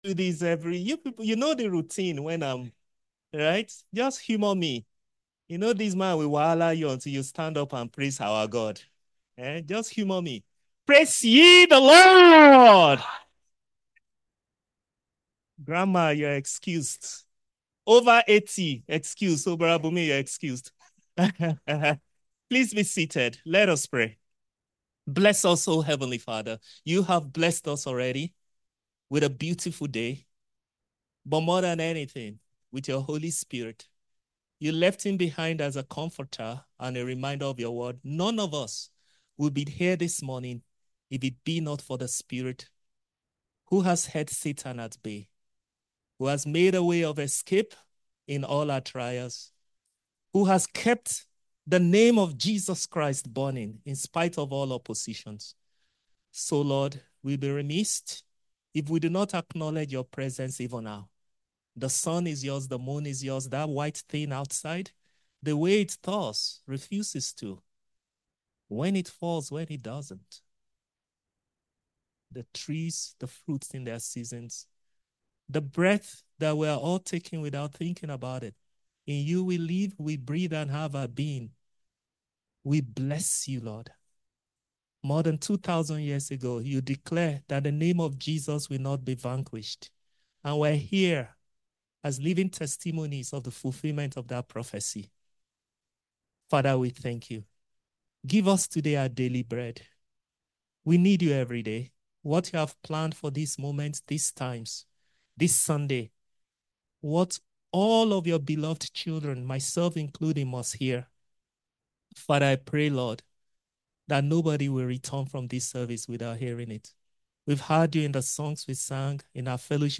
From Series: "Sermons"